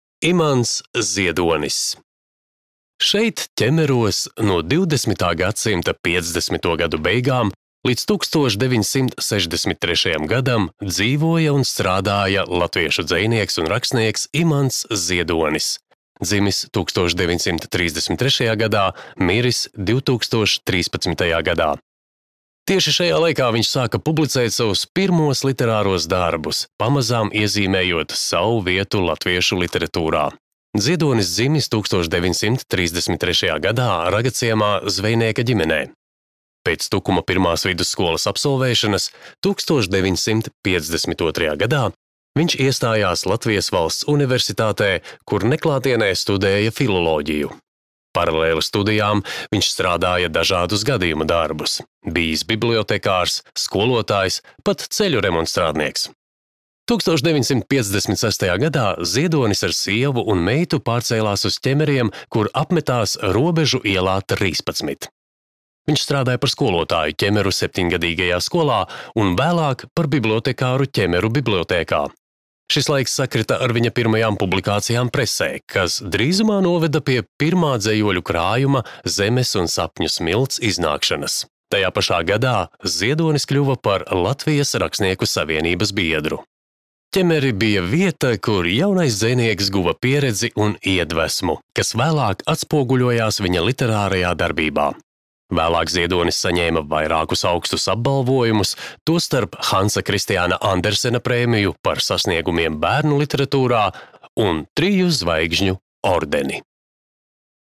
Ķemeru kūrorta parka audiogids